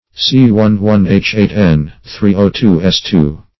Firefly luciferin \Fire"fly` lu*cif"er*in\, n. (Biochem.)